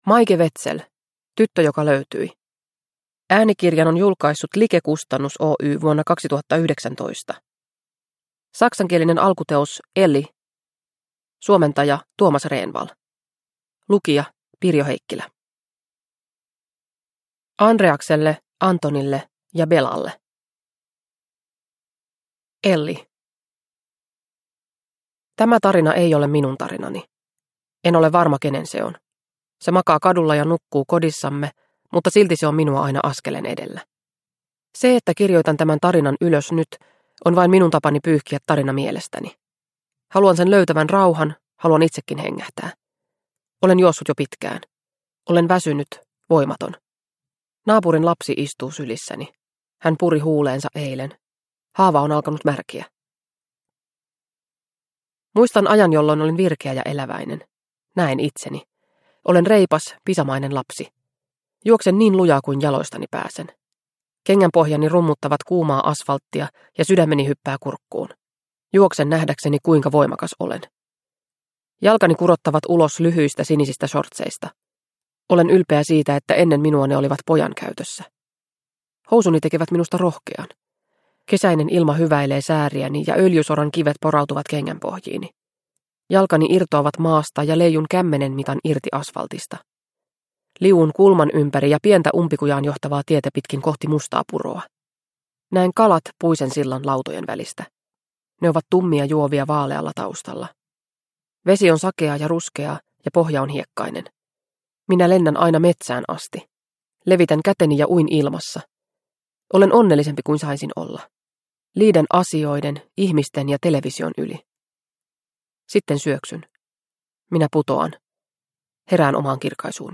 Tyttö joka löytyi – Ljudbok – Laddas ner